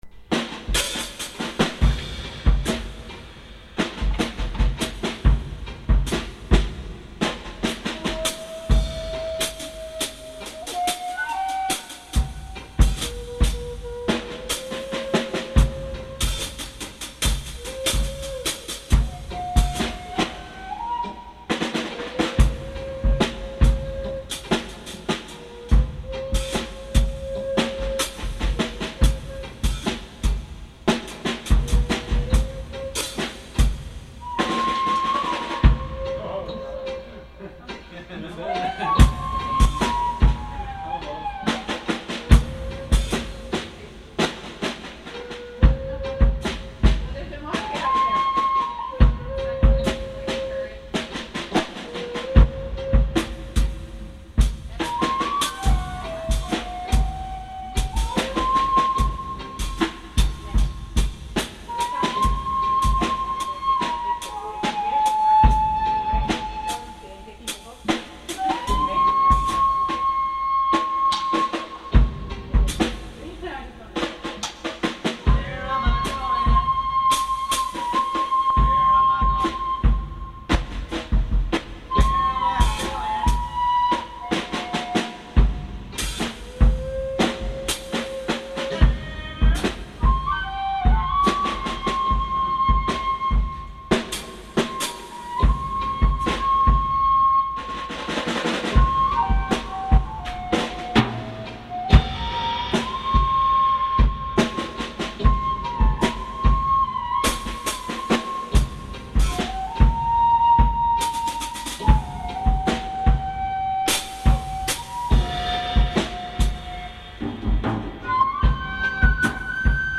Walking to the Beat of Your Own Inner Drummer - Djembe Rhythms from West Africa